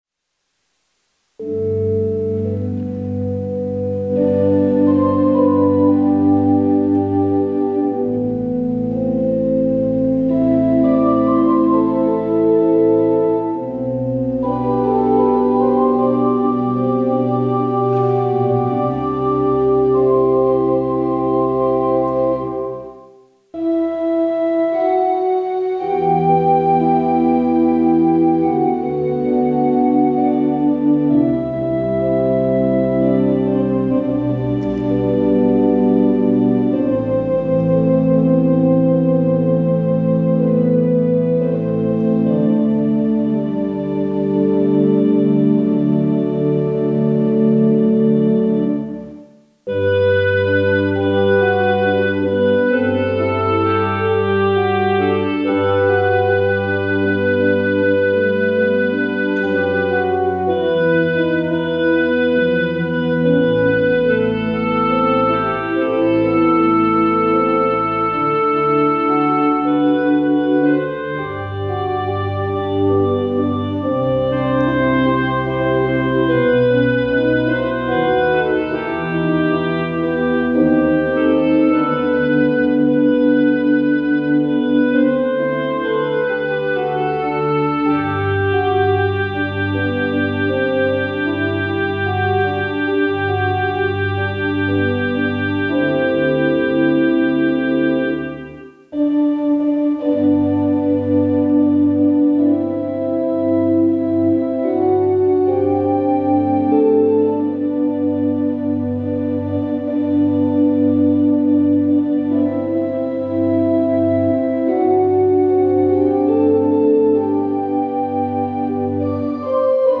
특송과 특주 - 누군가 널 위해 기도하네